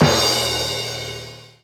Sound effect of Boom Boom Last Damage in Super Mario 3D World.
SM3DW_Boom_Boom_Last_Damage.oga